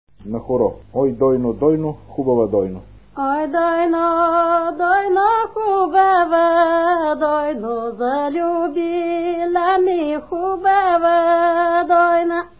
музикална класификация Песен
размер Две четвърти
фактура Едногласна
начин на изпълнение Солово изпълнение на песен
битова функция На хоро
фолклорна област Североизточна България
място на записа Кълново
начин на записване Магнетофонна лента